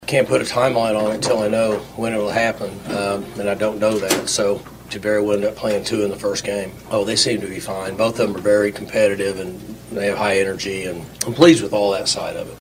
Oklahoma State head football coach Mike Gundy met with the media on Saturday for the first time since the Cowboy’s fall camp began. Gundy answered a lot of questions about the Pokes’ quarterback battle.